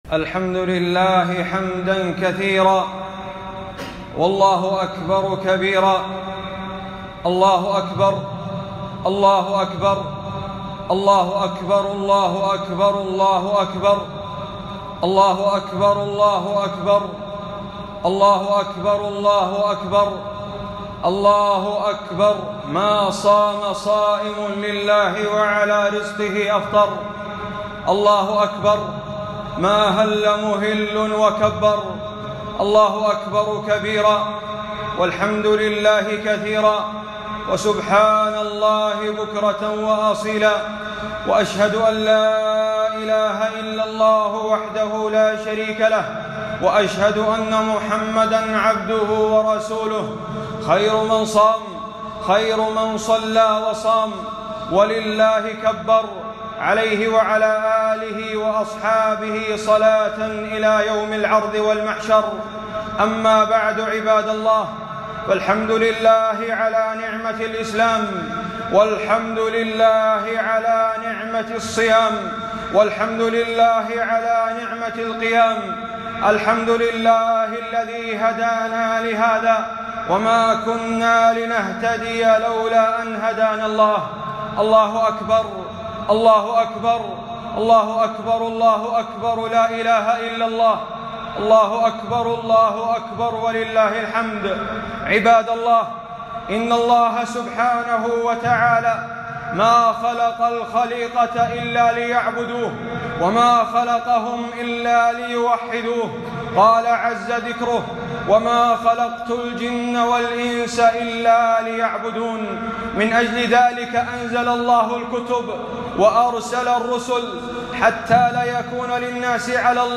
خطبة عيد الفطر ١٤٤٢هـ